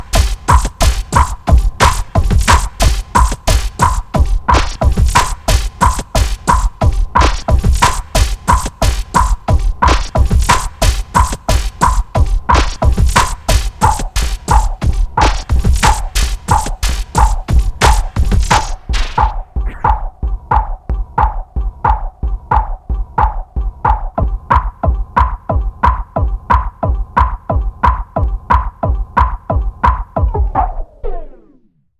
描述：雅马哈DJX录音，然后在DJ Pro中重新混合，在Magix中循环。
标签： 120 bpm Hardcore Loops Scratch Loops 5.38 MB wav Key : Unknown
声道立体声